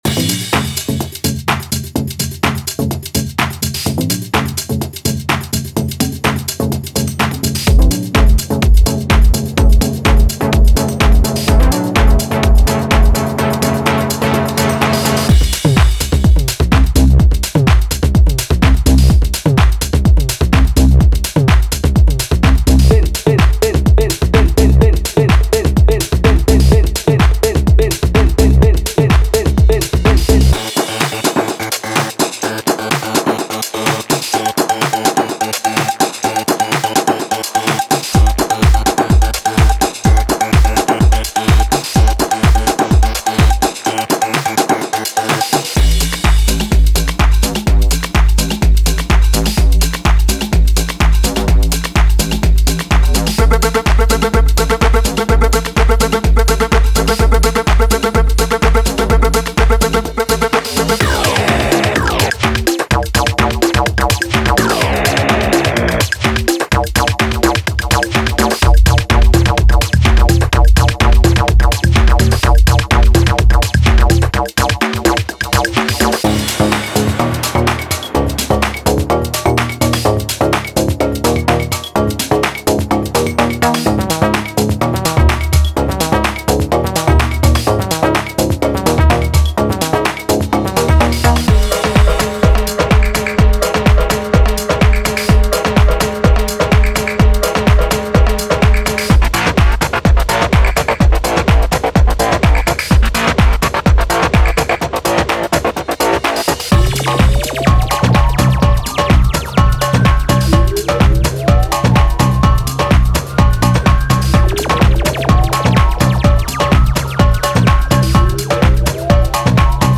ストンプするようなハウスのグルーヴを出すなら、素晴らしいパーカッションに勝るものはない！
柔軟なミックス＆マッチが可能な、分解されたドラム・ループ。
デモサウンドはコチラ↓
Genre:Tech House